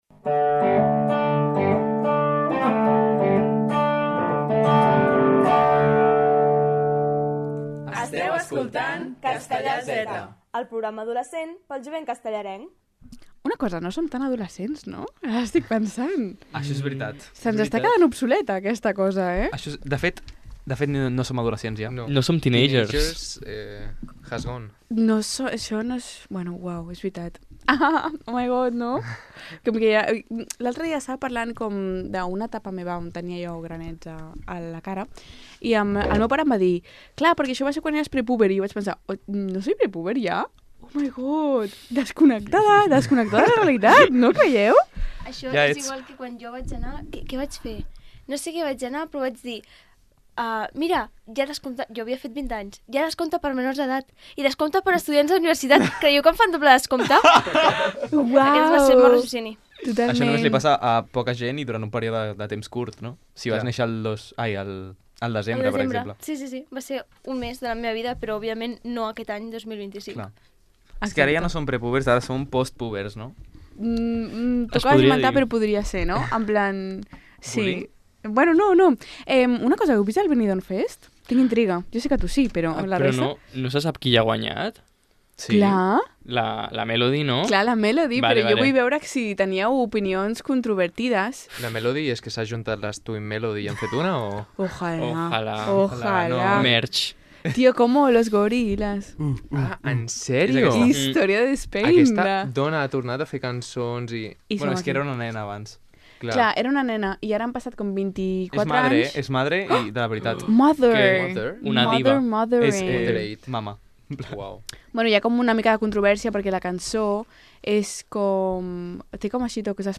Tertúlia d'activitat jove a Ràdio Castellar.